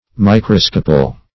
Search Result for " microscopal" : The Collaborative International Dictionary of English v.0.48: Microscopal \Mi*cros"co*pal\, a. Pertaining to microscopy, or to the use of the microscope.